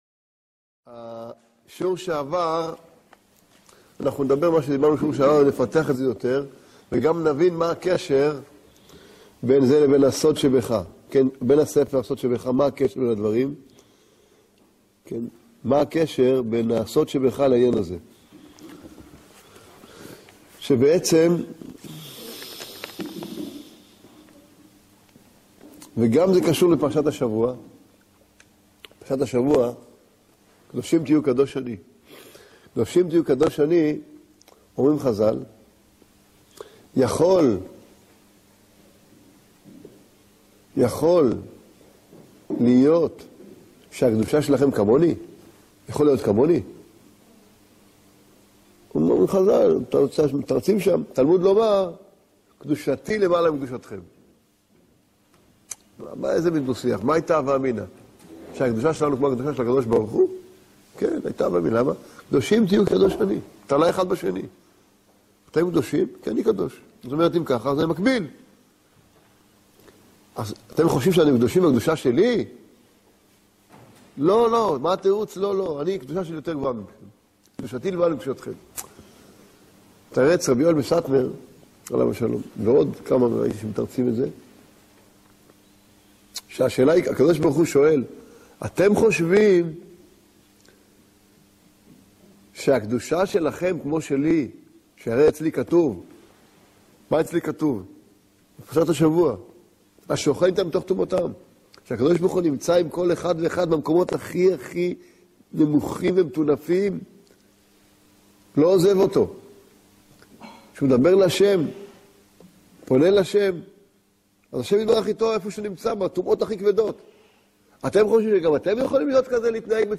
בשיעור זה נלמד על שמחה, שמירת העינים, עליות וירידות, גלגולים, יצר הרע, קריאת שמע, קדושה, טומאה, מצוות, עבירות, ספירת העומר, עקשנות ועוד…